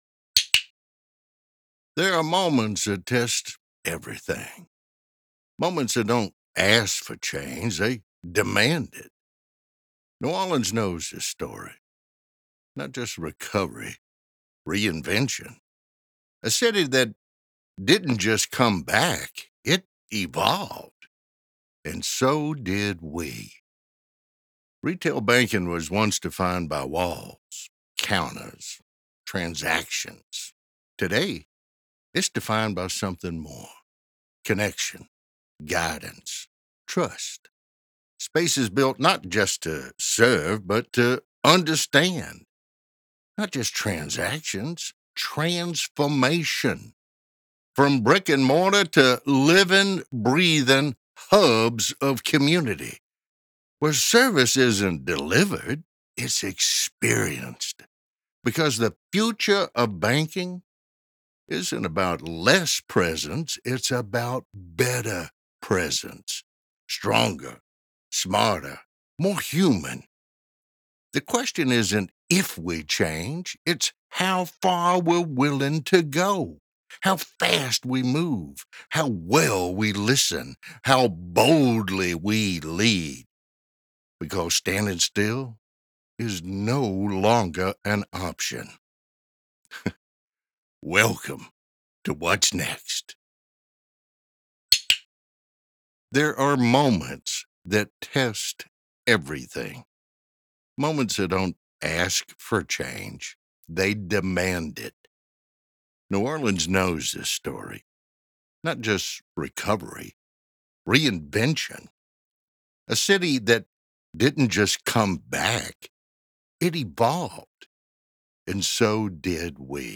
Professional Voice Over Artist
Business Video for New Orleans Post-Katrina
English Neutral, English - Southern US
Senior